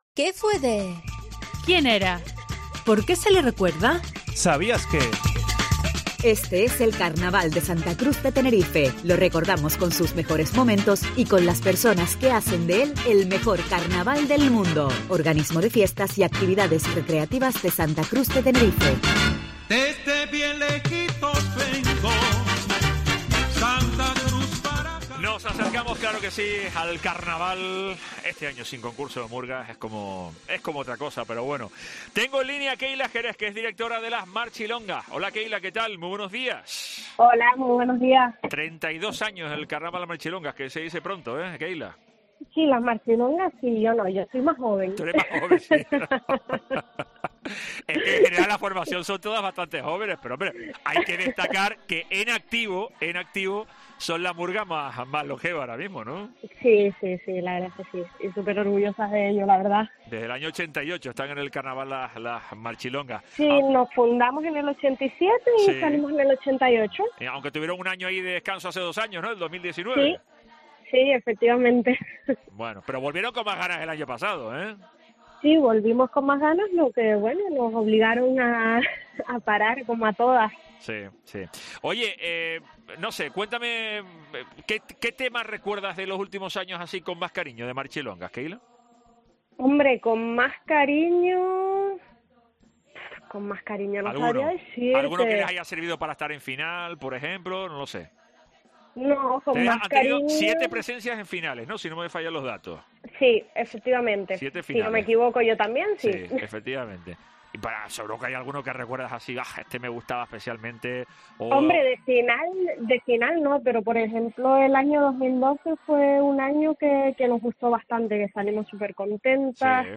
Carnaval de Tenerife